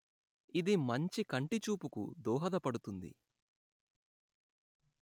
Automatic Speech Recognition